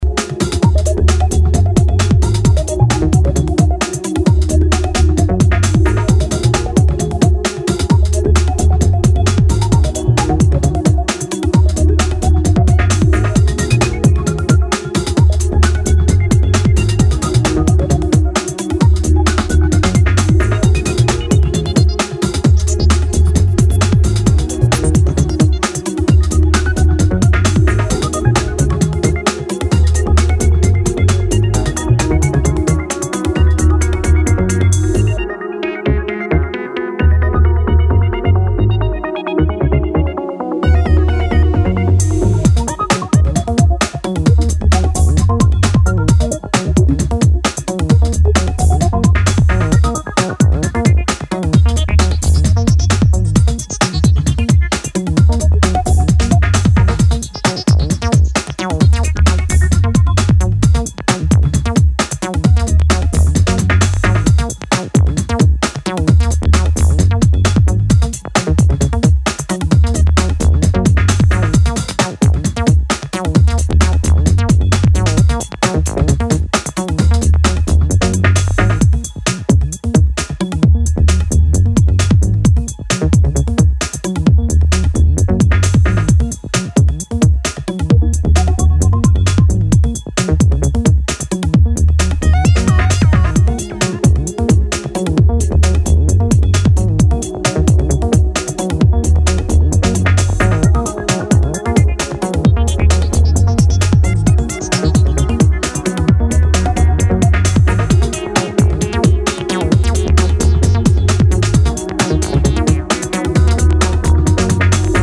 modern electronics crafted for the dancefloor